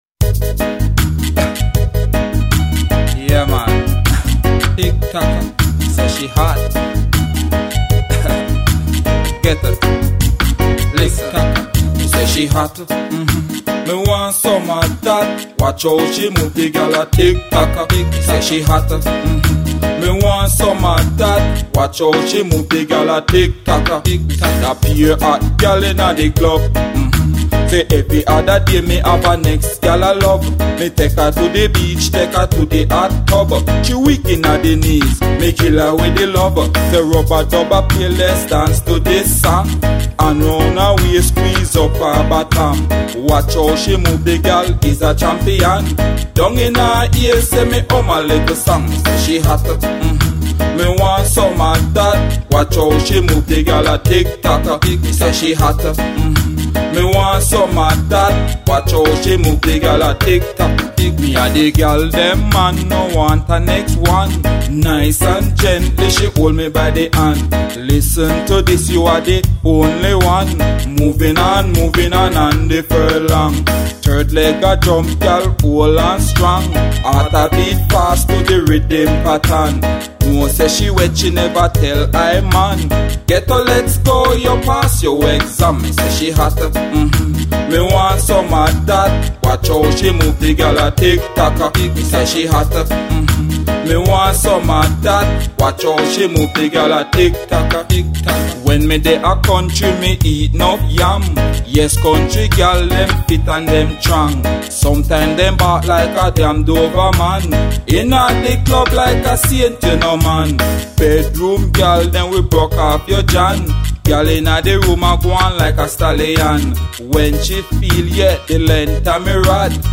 retro dancehall style vocals